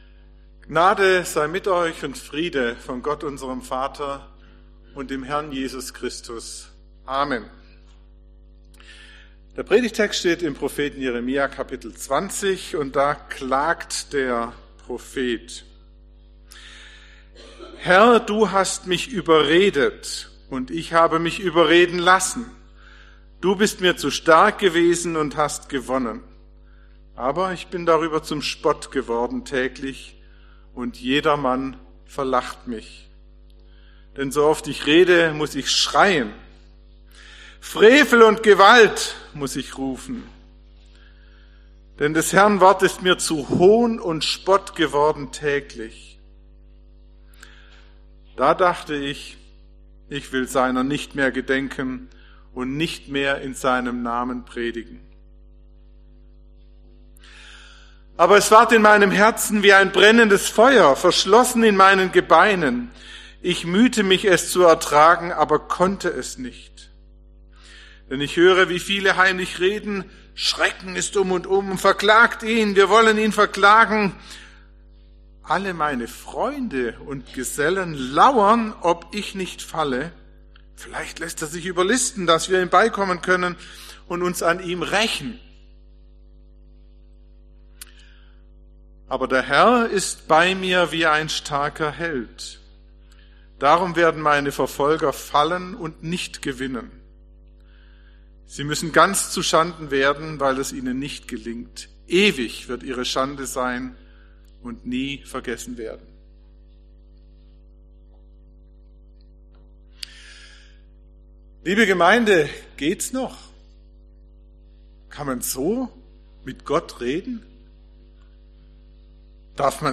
Die Predigten aus den Gottesdiensten unserer Gemeinde finden sie zum Nachlesen und Nachhören in diesem Podcast.